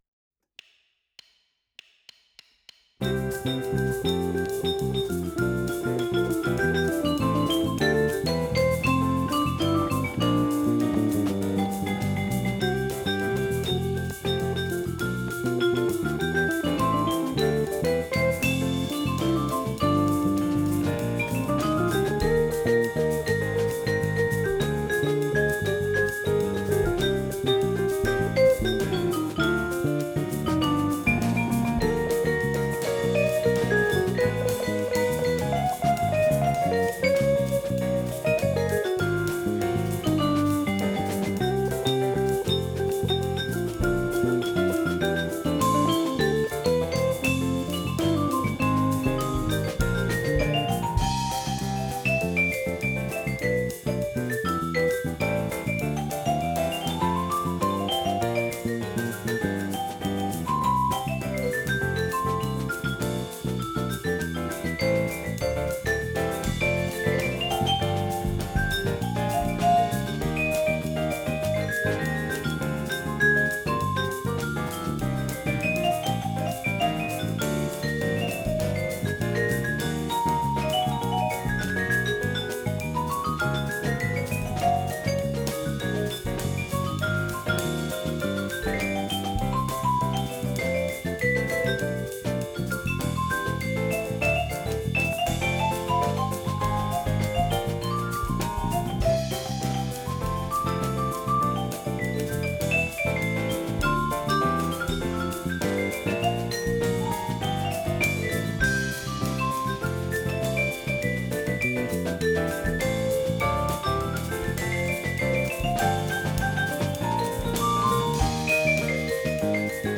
Vibraphon
• A=442 Hz
Klangbeispiel
Tonumfang: 3 Oktaven